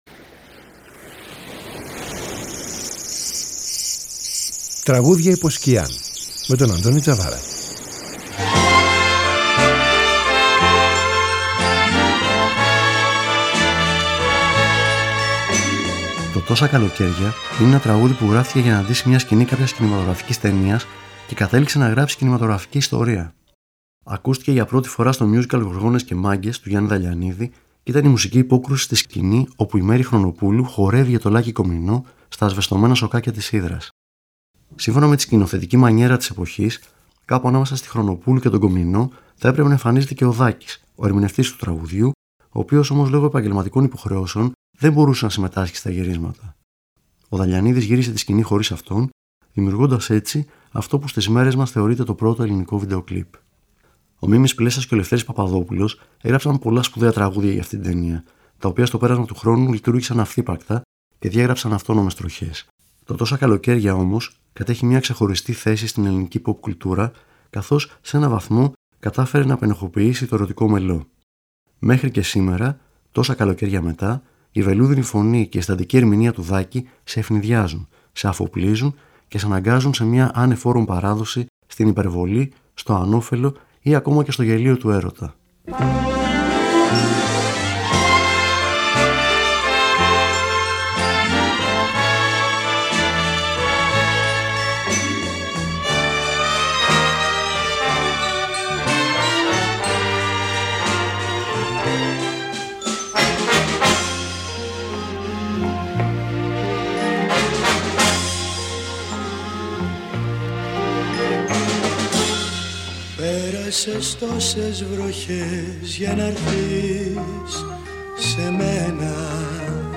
Μέχρι και σήμερα, τόσα καλοκαίρια μετά, η βελούδινη φωνή και η αισθαντική ερμηνεία του Δάκη, σε αιφνιδιάζουν, σε αφοπλίζουν και σε εξαναγκάζουν σε μια άνευ όρων παράδοση στην υπερβολή, στο ανώφελο ή ακόμα και στο γελοίο του έρωτα.